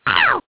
One of Toad's voice clips in Mario Kart DS